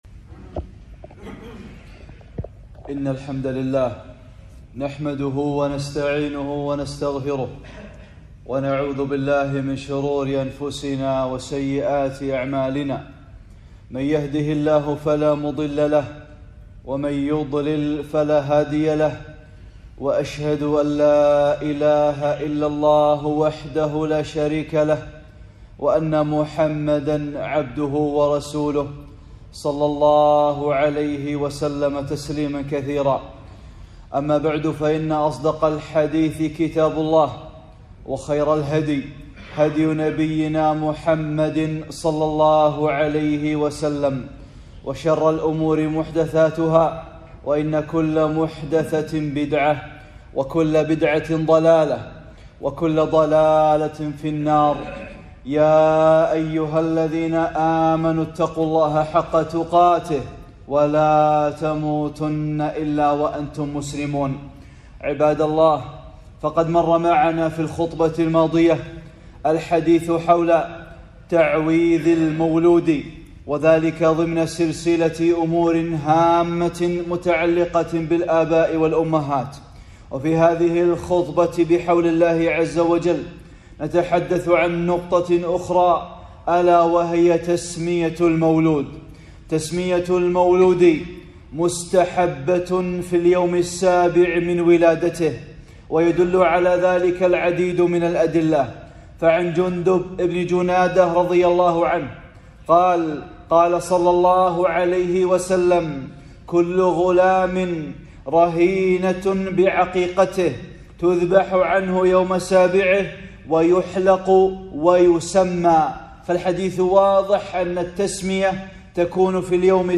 (15) خطبة - تسمية المولود - أمور هامة متعلقة بالآباء والأمهات